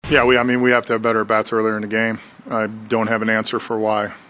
Manager Derek Shelton says the Pirates’ offense needs to be ready to go from the first pitch.